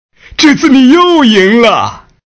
boss_die2.mp3